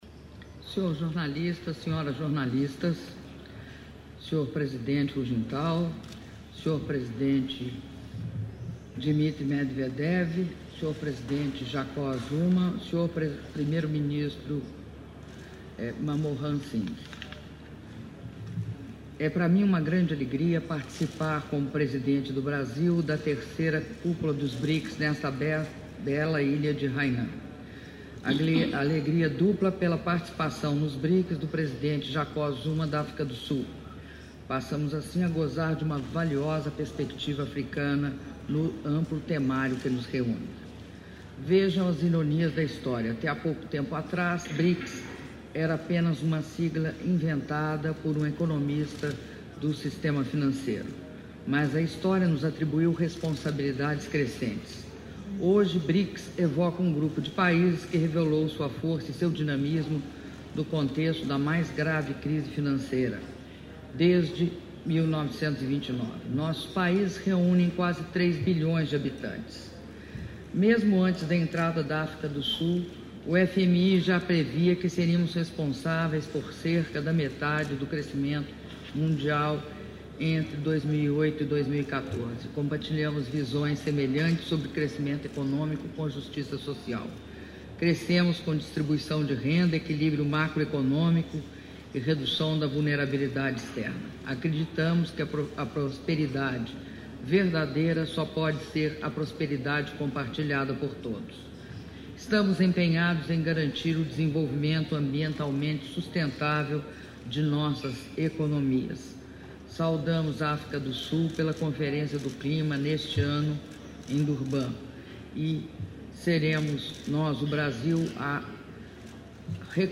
Declaração à imprensa concedida pela Presidenta da República, Dilma Rousseff, após 3ª Cúpula dos BRICS - Sanya/China
Sanya-China, 14 de abril de 2011